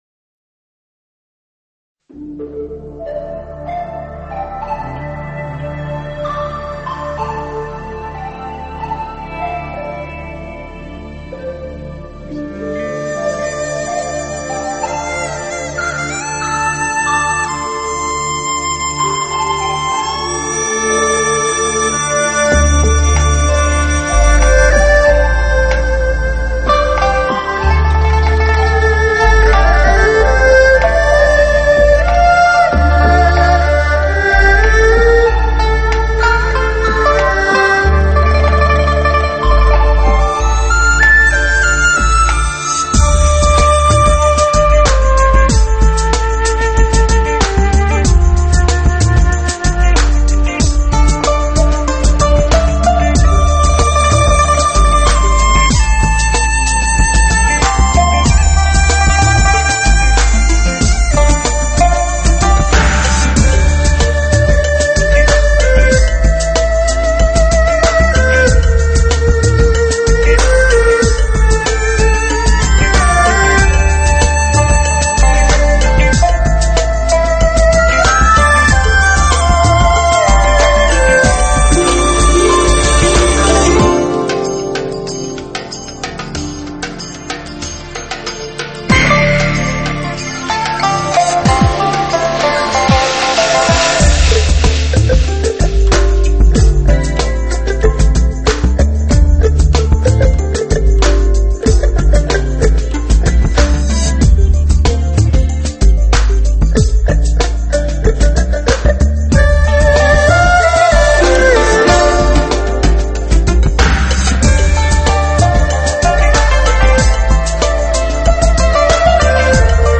古典乐器演绎新民乐曲风绚丽多采
种类齐全的民族乐器独奏、协奏、轮奏、合奏珠联壁合相得益彰。